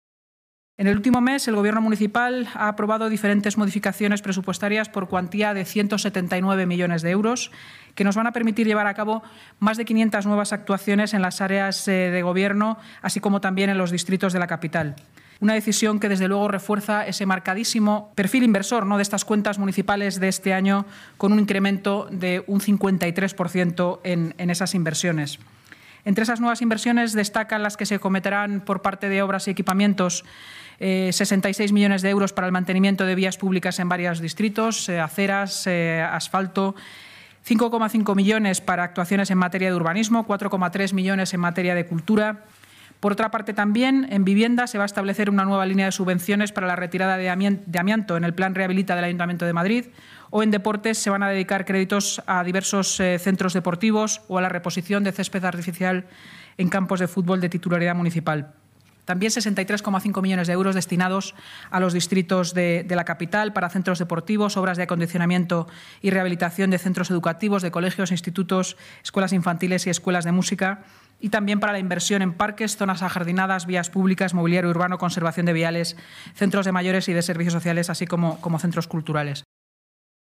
Inmaculada Sanz, portavoz municipal, durante la rueda de prensa posterior a la Junta de Gobierno, este jueves